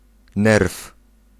Ääntäminen
Ääntäminen Tuntematon aksentti: IPA: /nɛrf/ Haettu sana löytyi näillä lähdekielillä: puola Käännös Konteksti Ääninäyte Substantiivit 1. nerve anatomia, eläintiede US Suku: m .